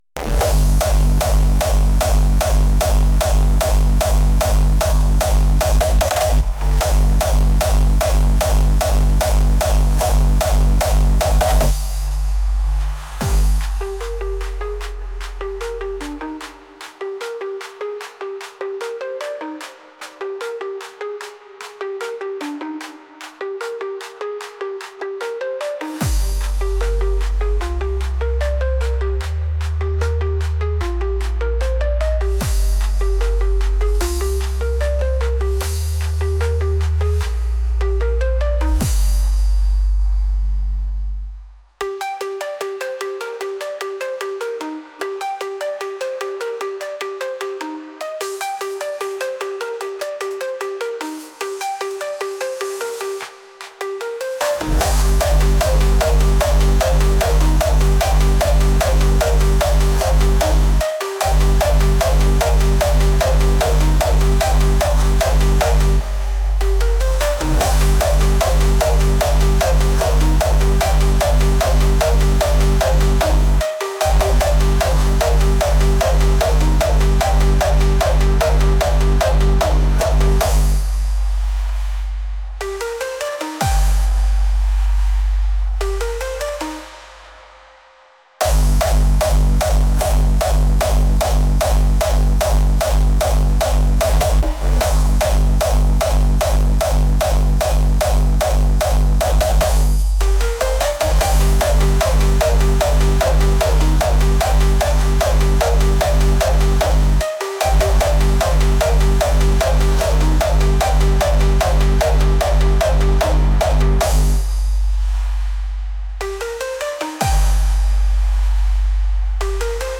intense | energetic